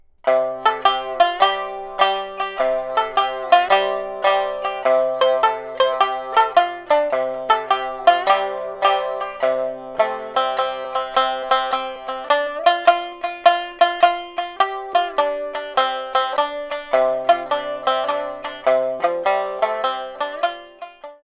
Fröhliches Obon-Tanzlied für Shamisen
• Niagari Stimmung (C-G-C)
• Hazunde (Shuffle) Rhythmus